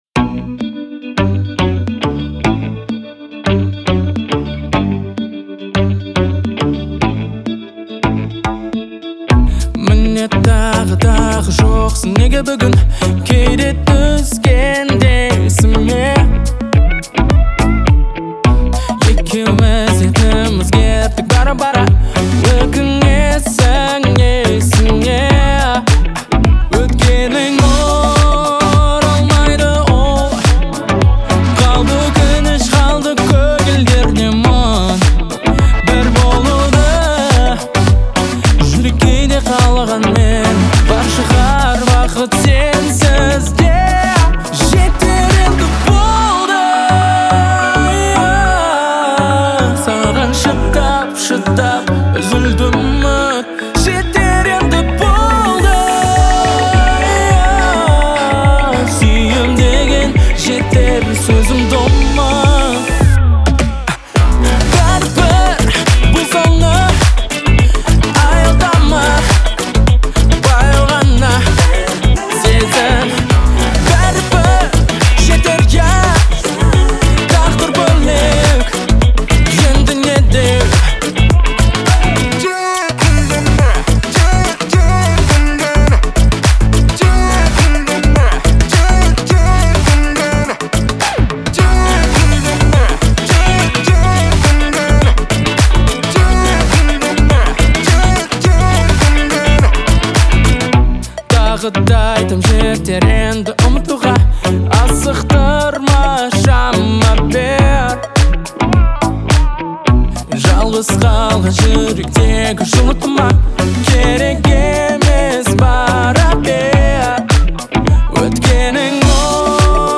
мощный вокал и трогательные мелодии